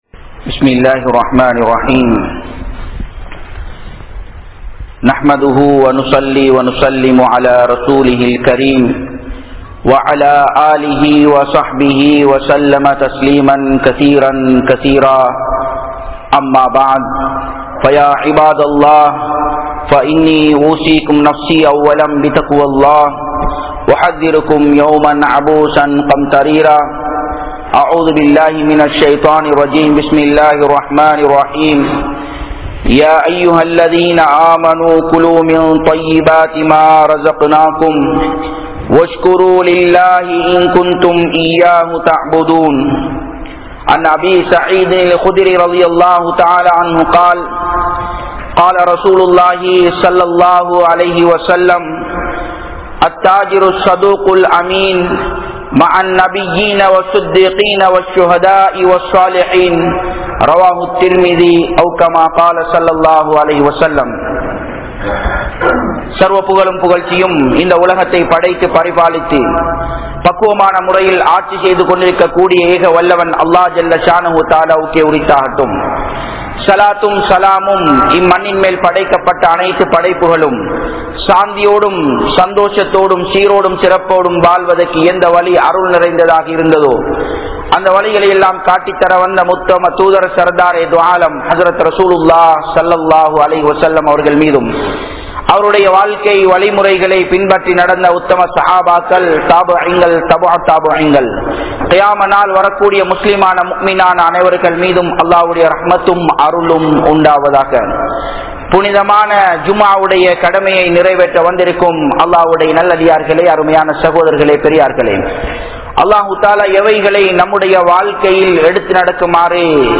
Japan, Nagoya Port Jumua Masjidh